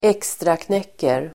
Uttal: [²'ek:straknek:er]